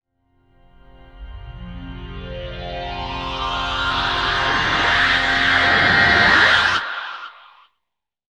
FMWIPE.wav